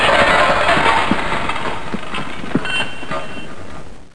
crackle.mp3